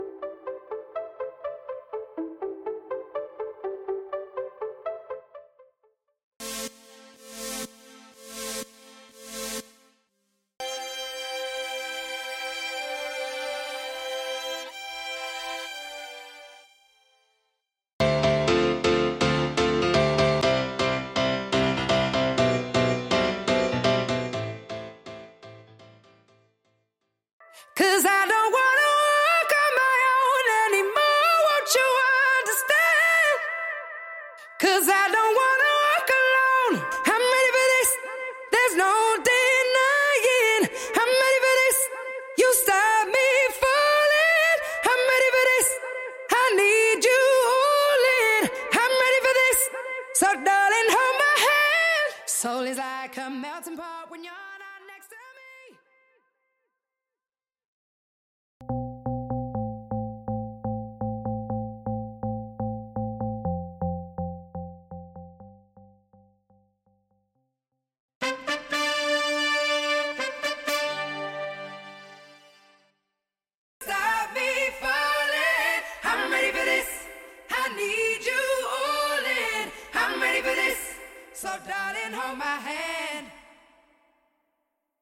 Studio Backing Vocals Stem
Studio Horns Stem
Studio Piano Stem
Studio Violin Stem